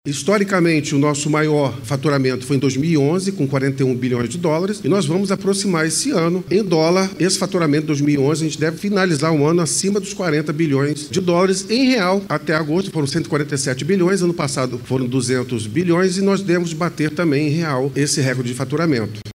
Igrejas também enfatizou a alta no faturamento do Polo Industrial de Manaus, que se aproxima do recorde registrado em 2011.